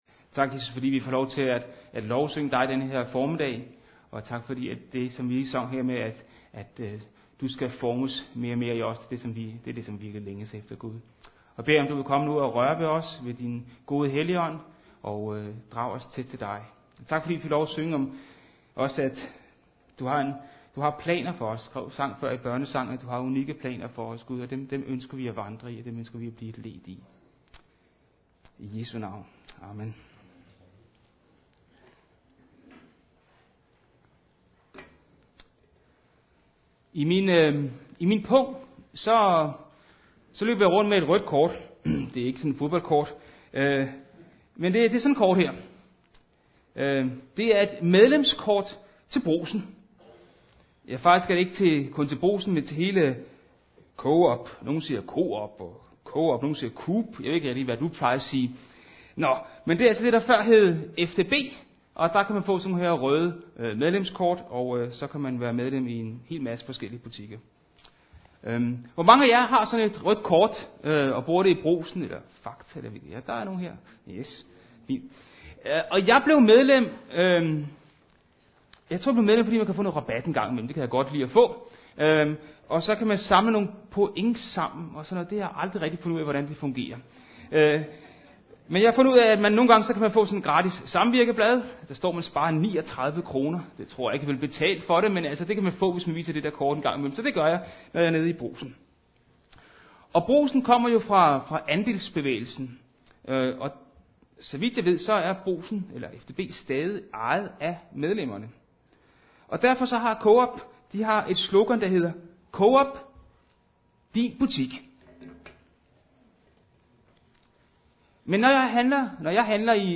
6. juli 2014 Type af tale Prædiken Bibeltekst Johannes Evangeliet MP3 Hent til egen PC